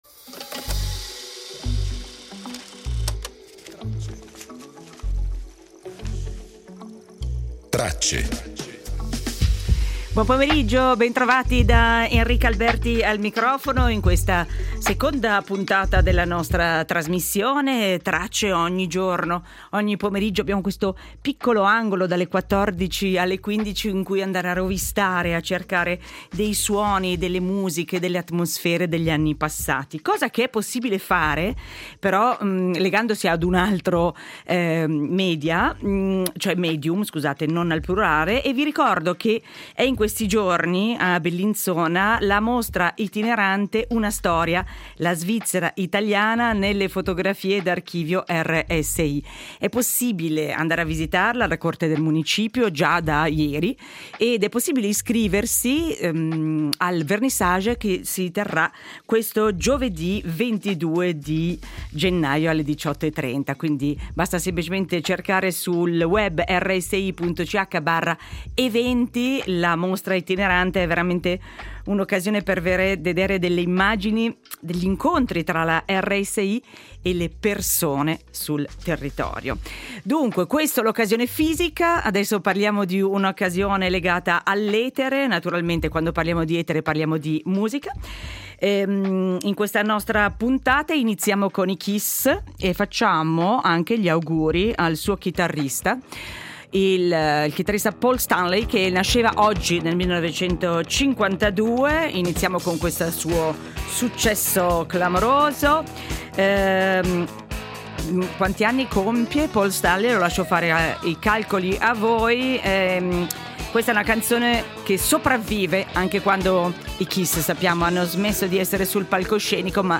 Ricordiamo il compleanno di Federico Fellini ascoltando la voce della sua compagna di vita Giulietta Masina.